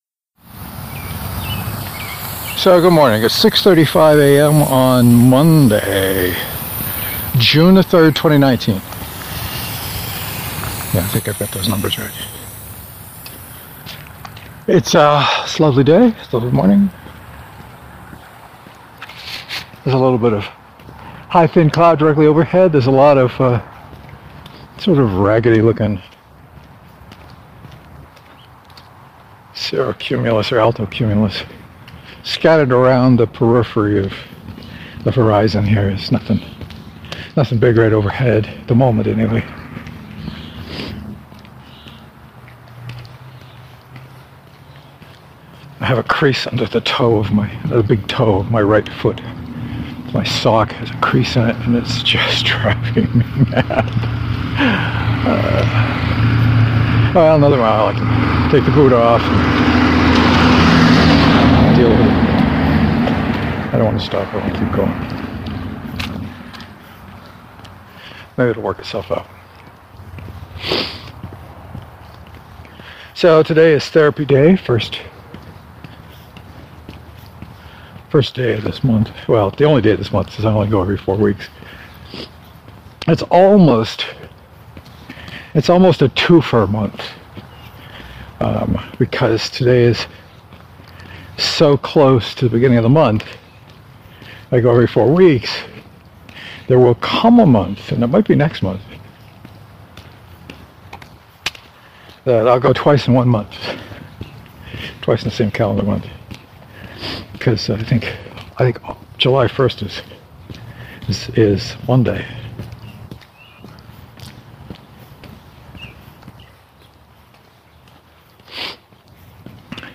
I managed to get underway this morning and talked a lot about the McKee book. I apparently talked most of the walk, just looking at the waveform on the audio.